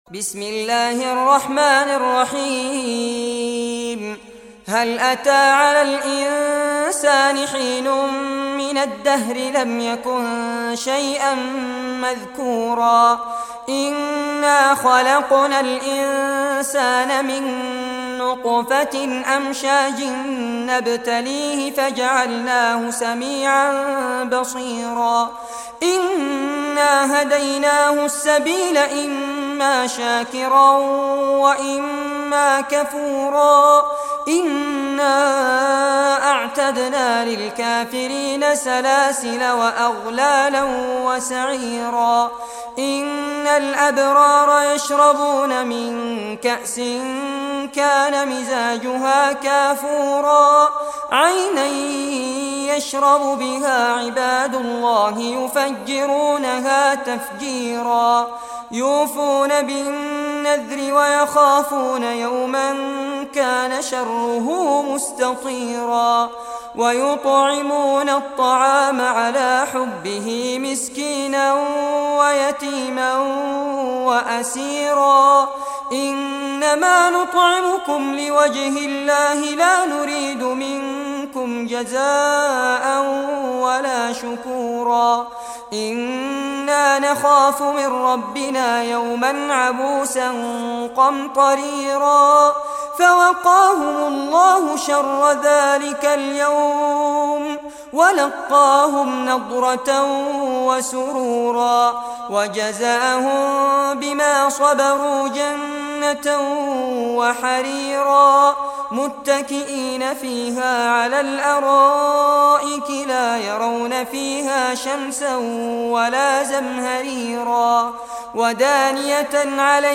Surah Al-Insan Recitation by Fares Abbad
Surah Al-Insan, listen or play online mp3 tilawat / recitation in Arabic in the beautiful voice of Fares Abbad.